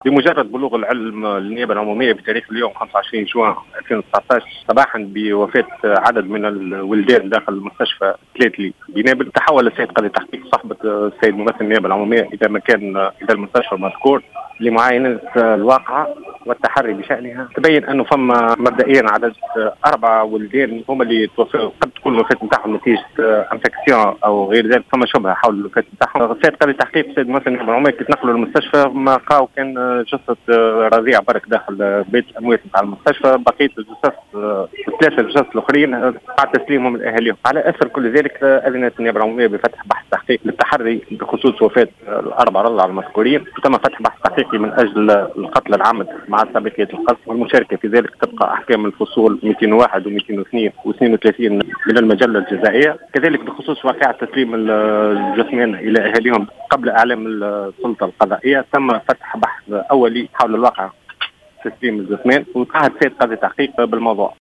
وأضاف بليلة في تصريح للجوهرة أف أم أنه مبدئيا يبدو أن هناك شبهة بخصوص وفاة 4 رضع التي قد تكون بسبب تعفن جرثومي،مشيرا إلى أن النيابة العمومية أذنت بفتح بحثين في الموضوع، الأول ، 'من أجل جريمة القتل العمد مع سابقية القصد والمشاركة في ذلك'، والثاني حول عملية تسليم جثامين الولدان دون إعلام السلط القضائية.